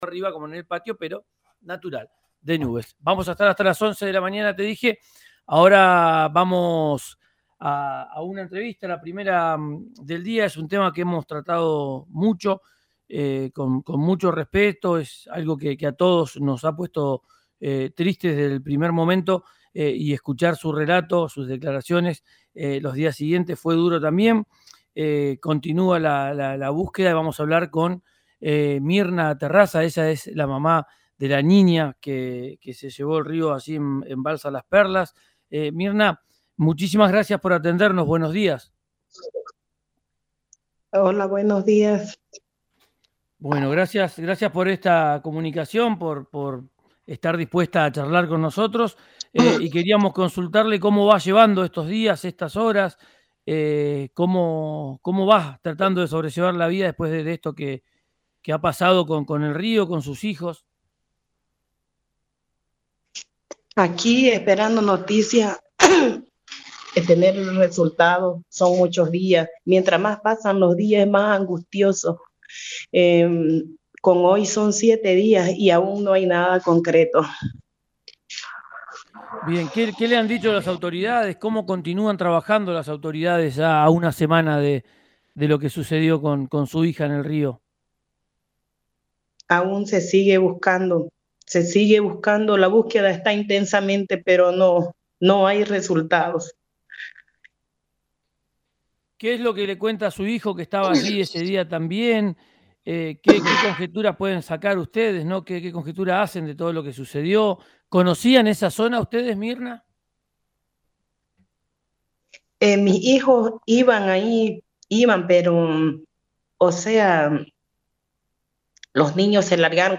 En una entrevista con RIO NEGRO RADIO